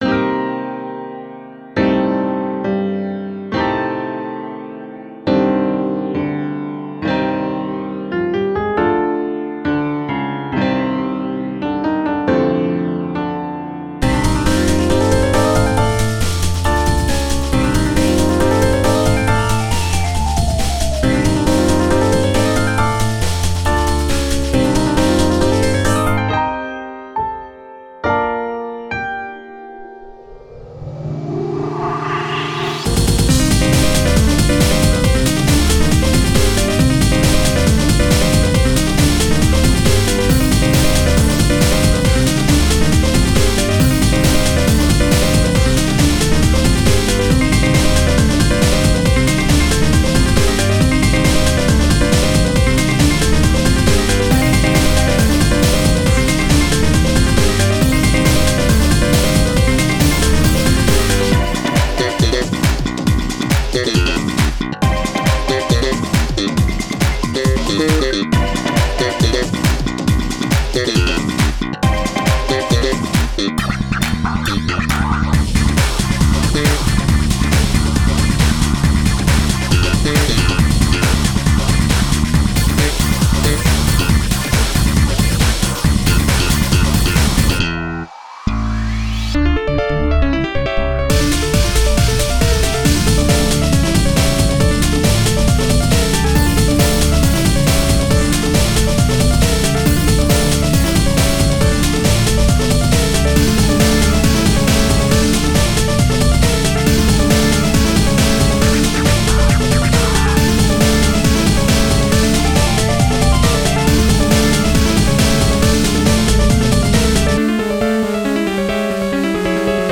That super fast piano riff has a really nice groove.
Drum N Bass